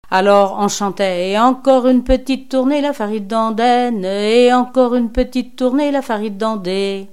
Sainte-Gemme-la-Plaine
Pièce musicale inédite